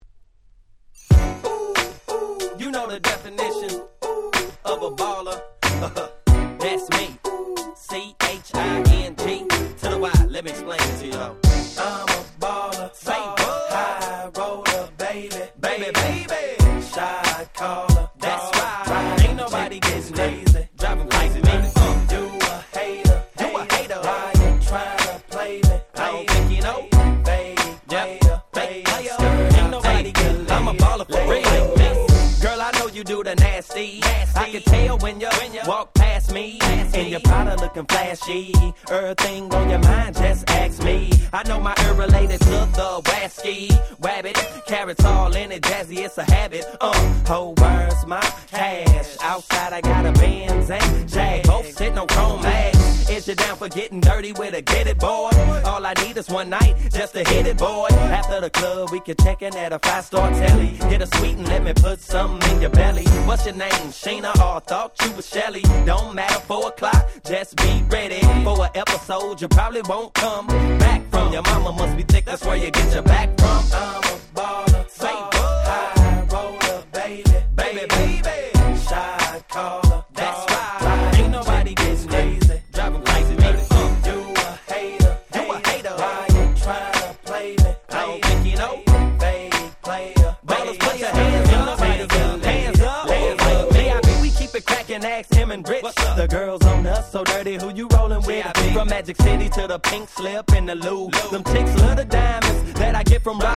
04' Super Hit Hip Hop !!
ウエッサイの曲みたいなBeatで大ヒット！(笑)
普通にGangsta Rapなんかとも混ぜれそうです。
説明不要の00's Hip Hop Classicsです。